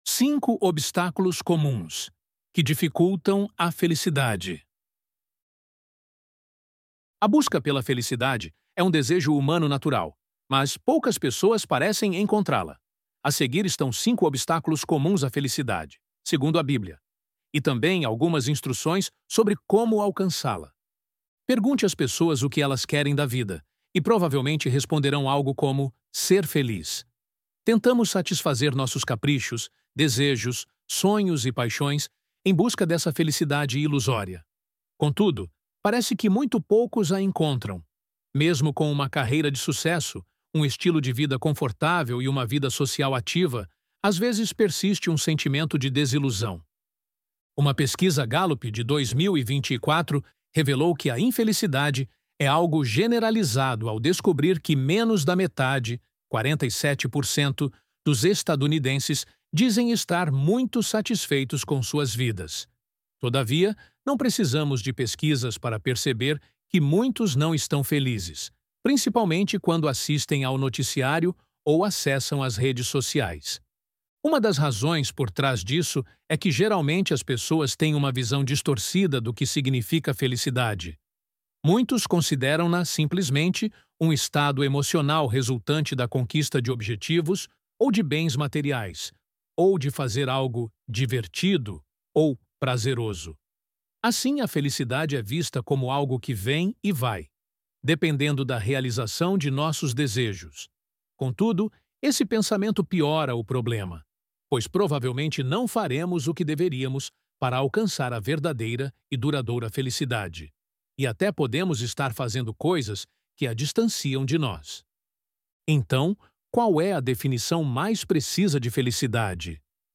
ElevenLabs_Cinco_Obstáculos_Comuns_Que_Dificultam_A_Felicidade.mp3